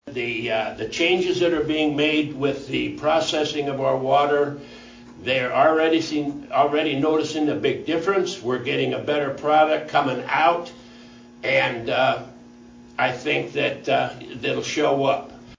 Mayor Phil Przychodizin, speaking at the recent Massena Annual Chamber of Commerce banquet, announced that the city is partnering with Greenfield Municipal Utilities to enhance the water treatment process.